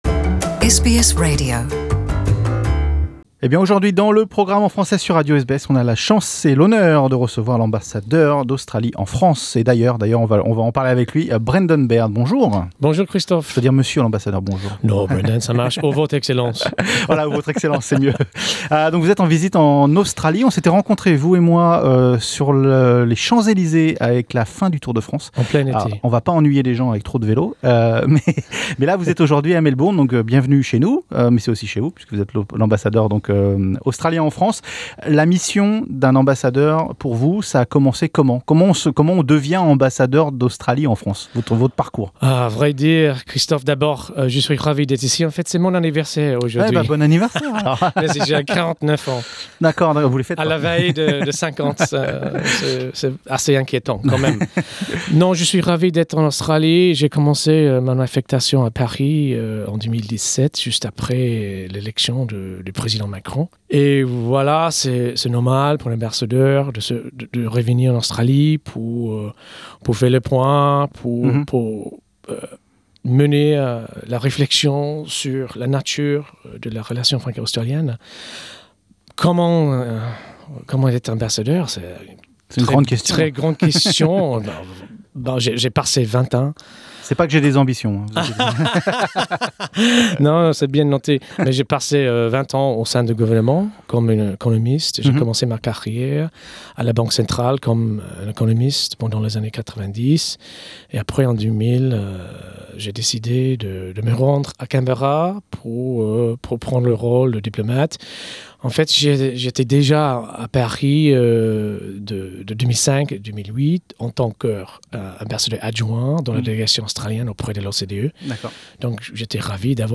Rencontre avec Brendan Berne, l'ambassadeur d'Australie en France, Algerie, Mauritanie et Monaco